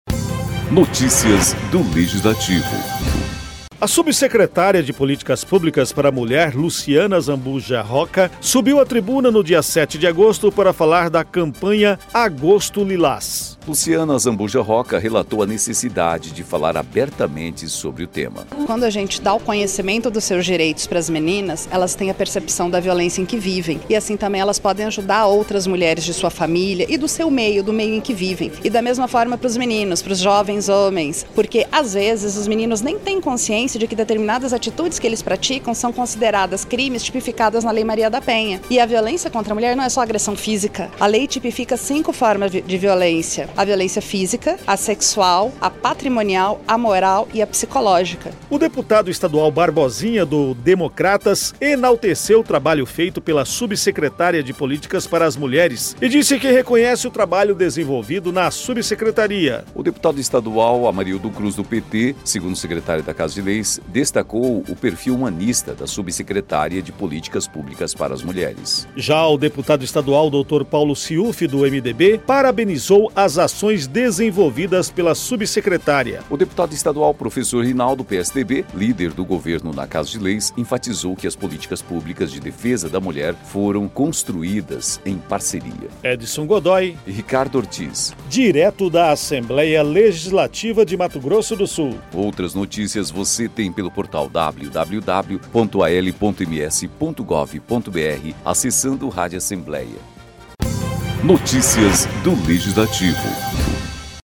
A subsecretária de Políticas Públicas para a Mulher, Luciana Azambuja Roca, subiu à tribuna nesta manhã (7) para falar da Campanha Agosto Lilás.